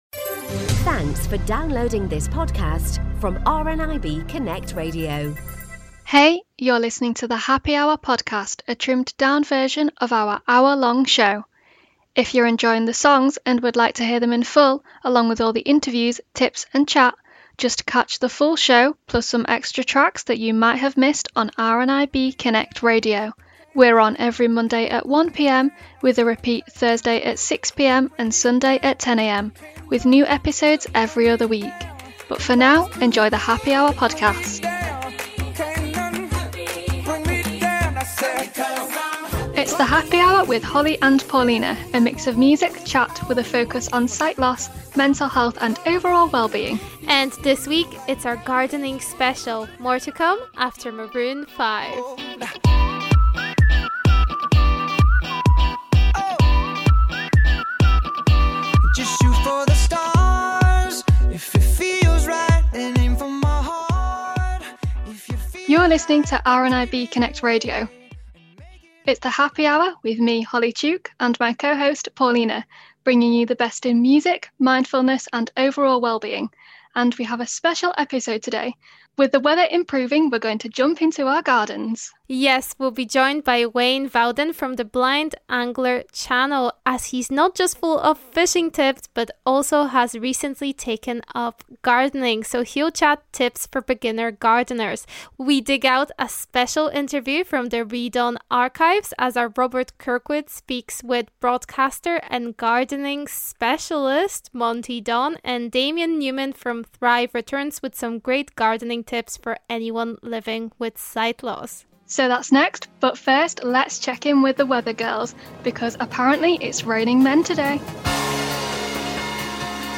And finally, we dig into the Read On archive, our fellow RNIB Connect Radio show all about books, for a conversation with horticulturist and host of BBC's Gardeners' World, Monty Don.